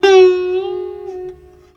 SITAR LINE61.wav